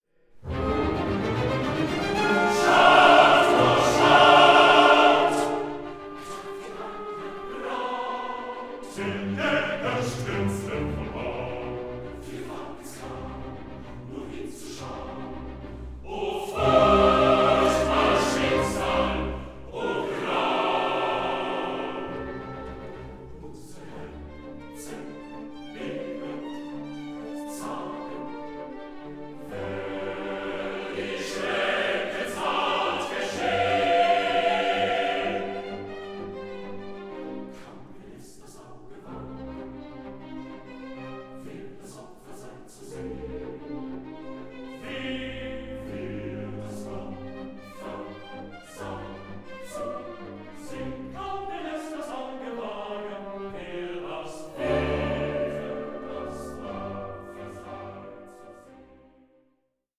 THE GERMAN ROMANTIC OPERA PAR EXCELLENCE